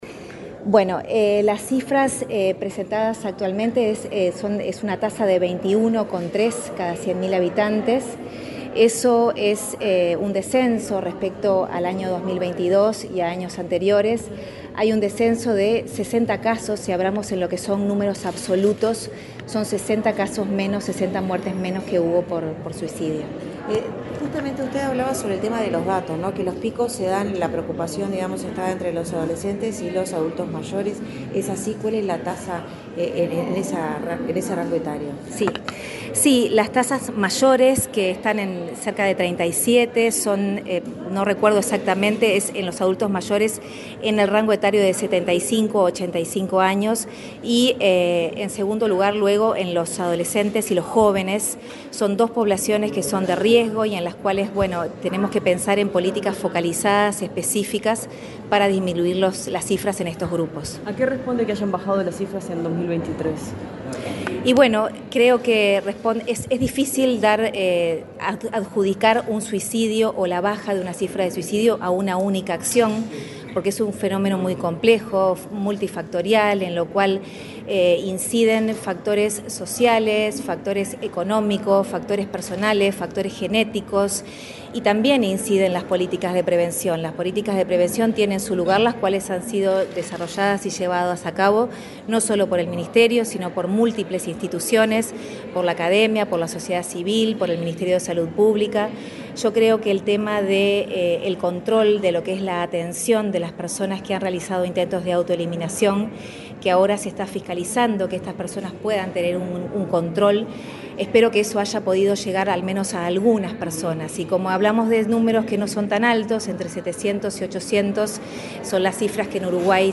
dialogó con la prensa, luego de participar en el acto de lanzamiento de una guía práctica para el abordaje del suicidio.